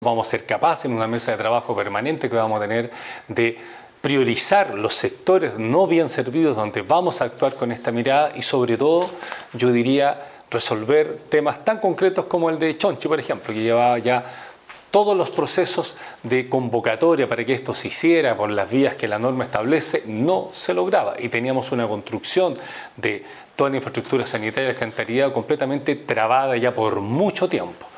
La máxima autoridad de la Región añadió que el seguimiento a este convenio se realizará en base a una mesa de trabajo entre los dos entes donde se verán proyectos de larga data como uno que se desarrolla en Chonchi.